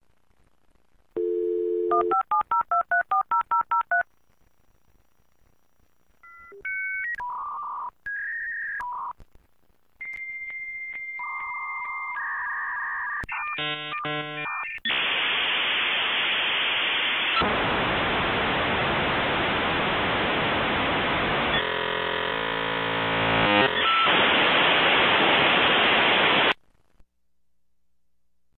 File:Dial-up modem noises.ogg - Eamon Wiki
Dial-up_modem_noises.ogg.mp3